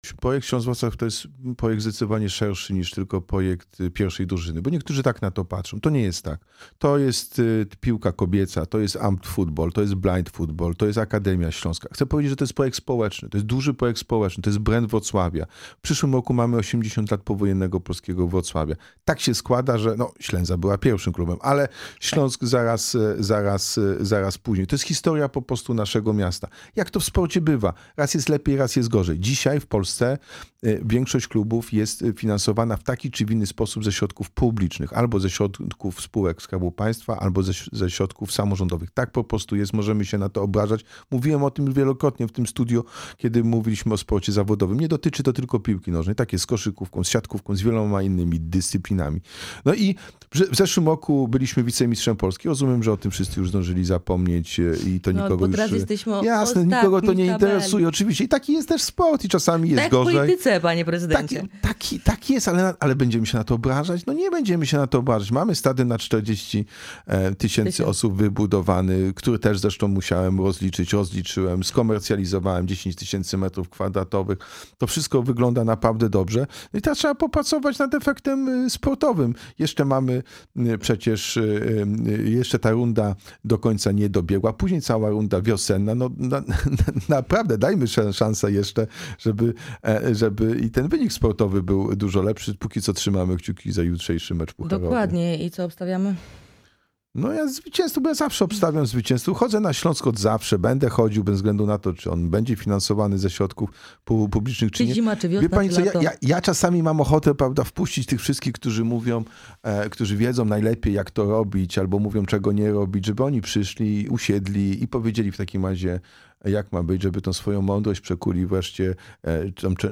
Projekt budżetu na 2025 r., audyt w piłkarskim Śląsku Wrocław, sprawa zarzutów ws. Collegium Humanum – to główne tematy w naszej rozmowie z prezydentem Wrocławia.